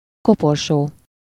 Ääntäminen
Synonyymit bière boîte caisse sapin mazout Ääntäminen France: IPA: /sɛʁ.kœj/ Haettu sana löytyi näillä lähdekielillä: ranska Käännös Ääninäyte Substantiivit 1. korporsó Muut/tuntemattomat 2. koporsó Suku: m .